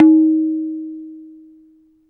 tun stroke
CompMusic Indian-percussion Tabla Tabla-stroke sound effect free sound royalty free Music